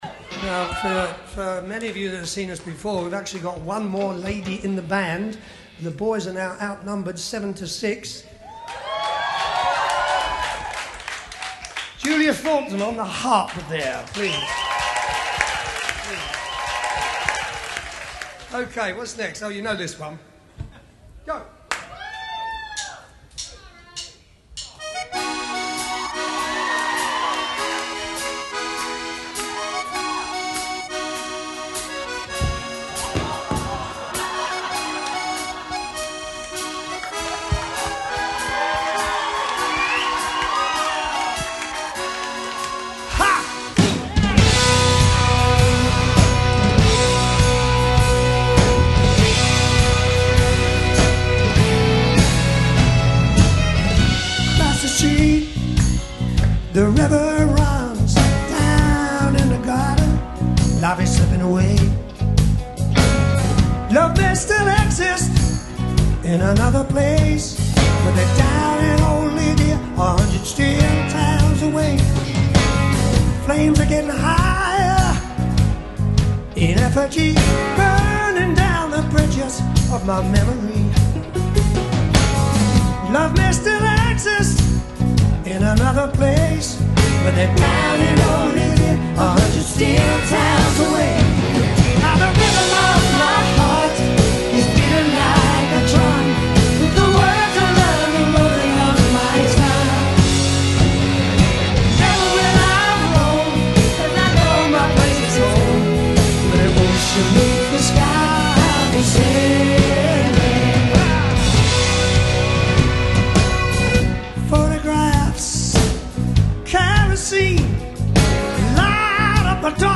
violinist